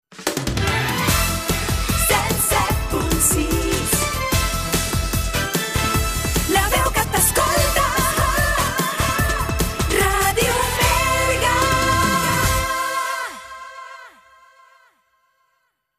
Indicatiu cantat de la ràdio